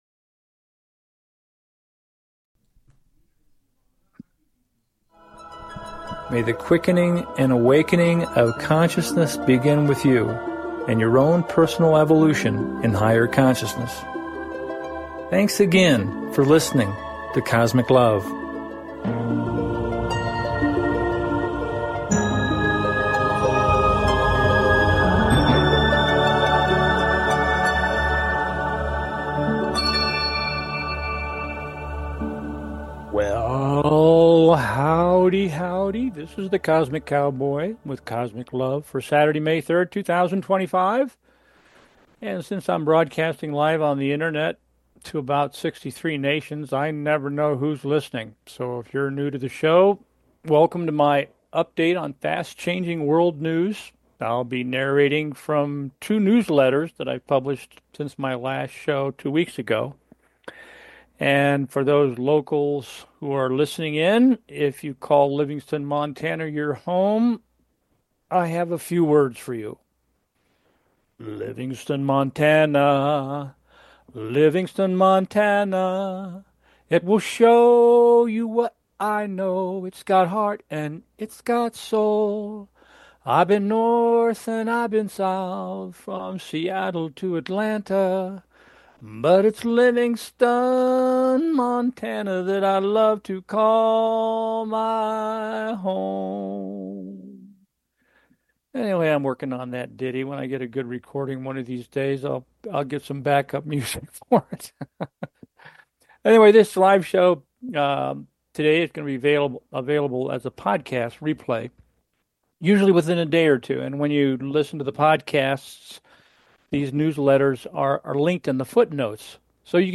Cosmic Weather Report and News with Cosmic ViewsDiscussing these recent newsletters: